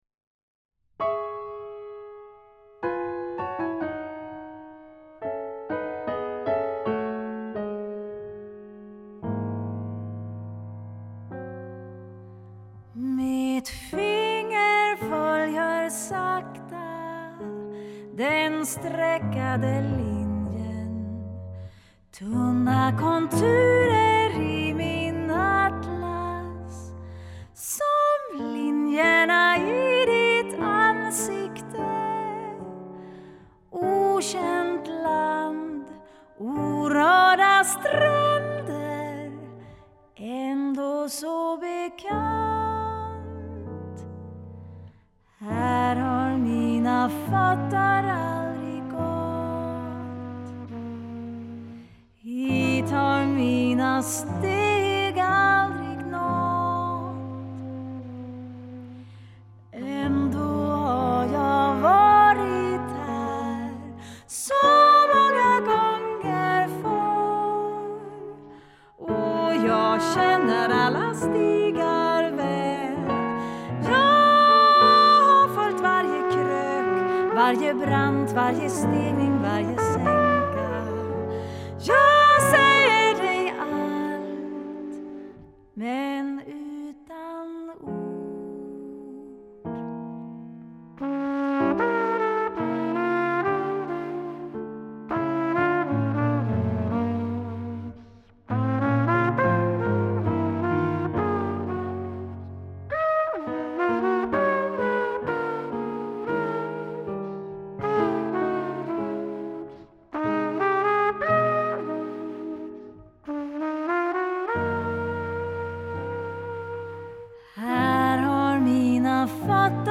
sång
trumpet
piano